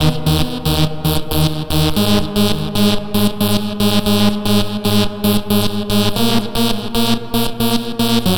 TSNRG2 Lead 017.wav